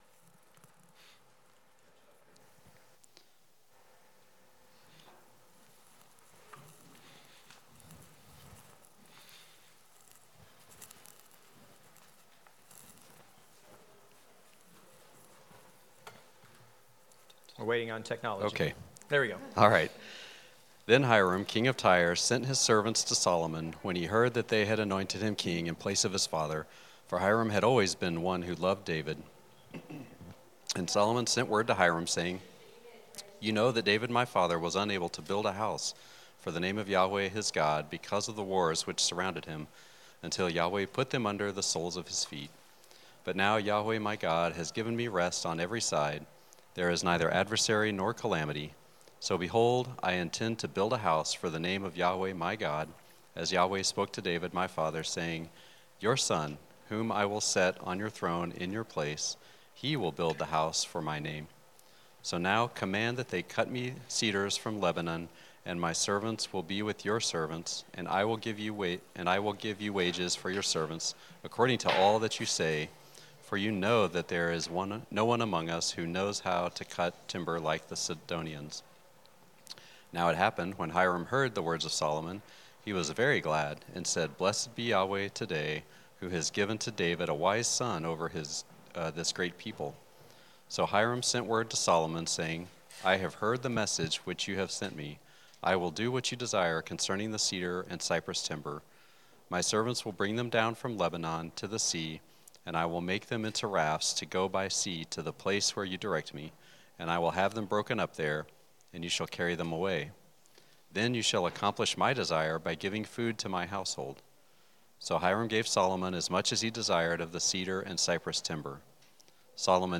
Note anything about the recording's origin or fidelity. Passage: 1 Kings 5-8 Service Type: Sunday School